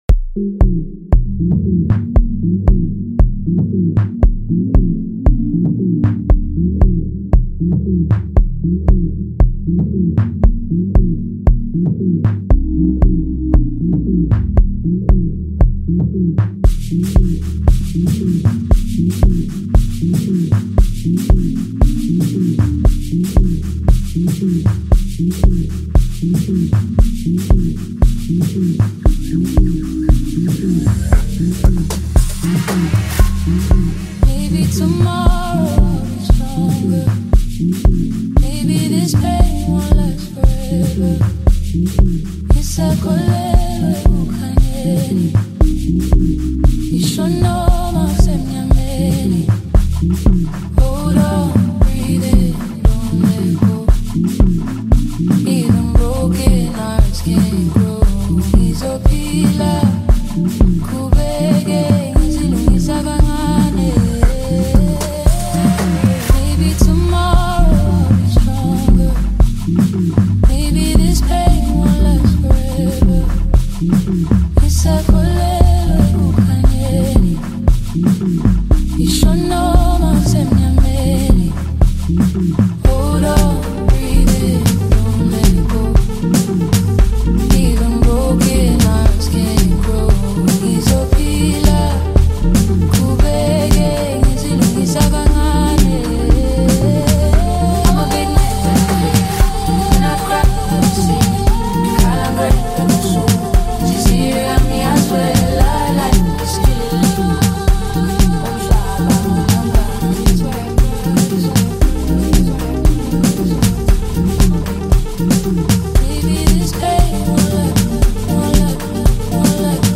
” offering smooth vocals
rich production